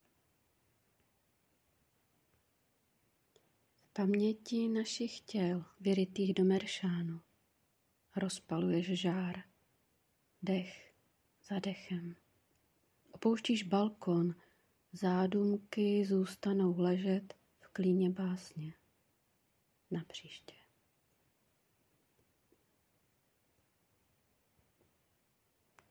Básně » Ostatní
Mám rád tvůj přednes a hlas*